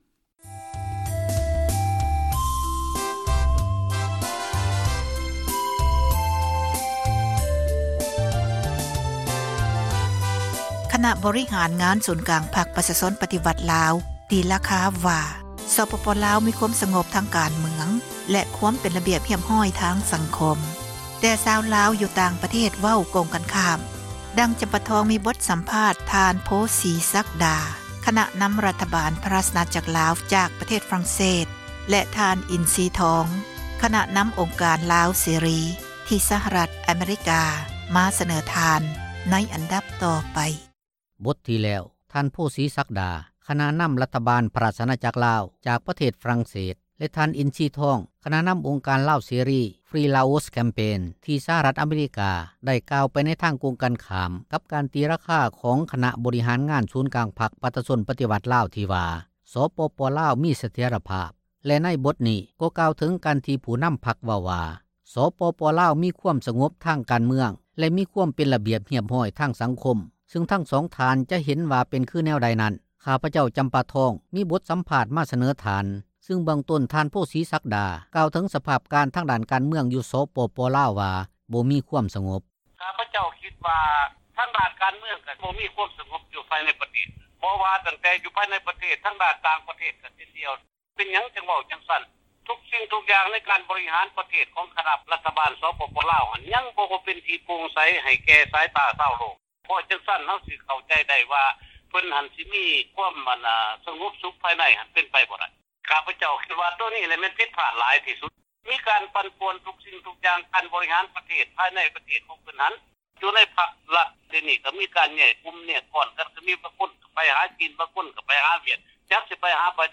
ມີບົດສໍາພາດ